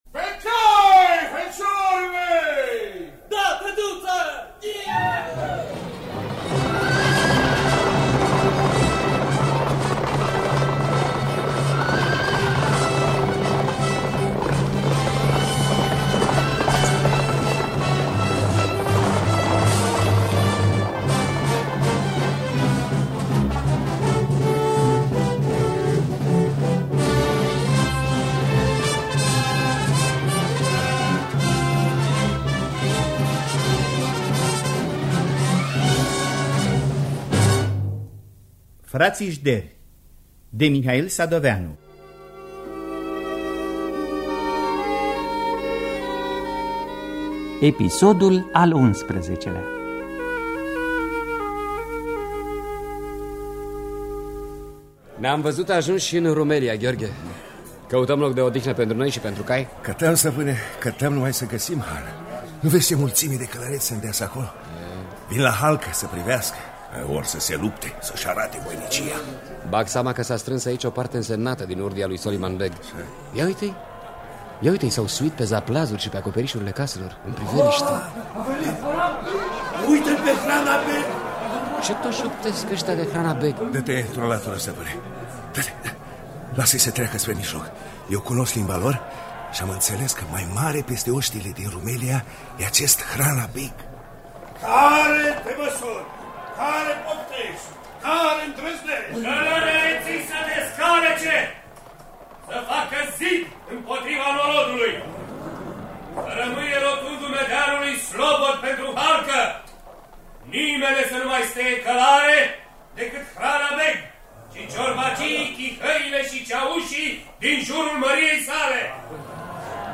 Mihail Sadoveanu – Fratii Jderi (1988) – Episodul 11 – Teatru Radiofonic Online